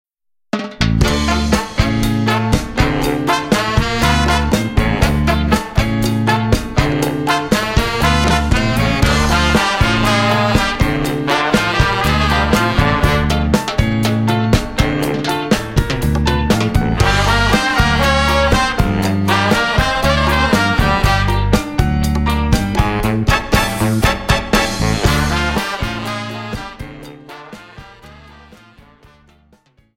【Latin American】
CHA-CHA-CHA